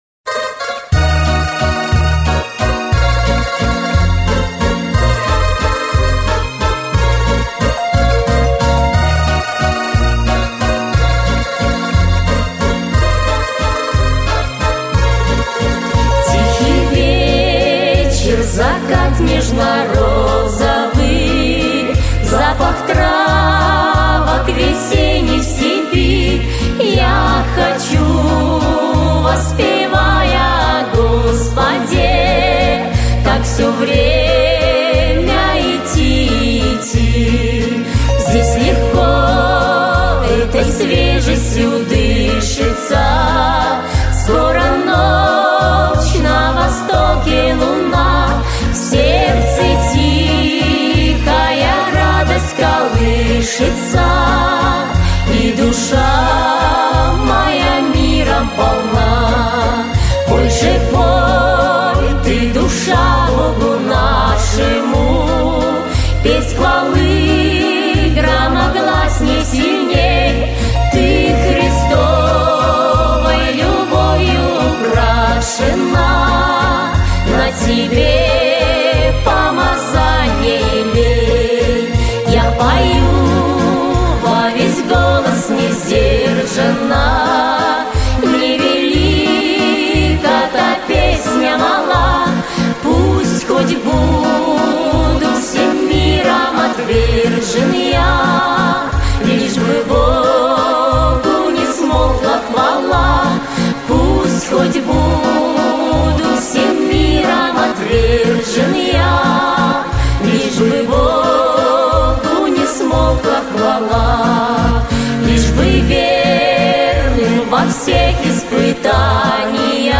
Христианская музыка